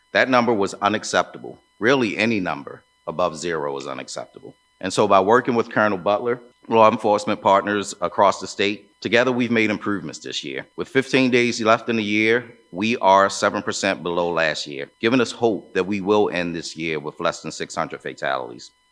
Maryland Remembers Ceremony Honors Crash Victims
Last year, 621 people were killed on state roads, underscoring the need for increased safety and awareness. MDOT Assistant Secretary Tony Bridges says that he is committed to lowering that number this year and in the future…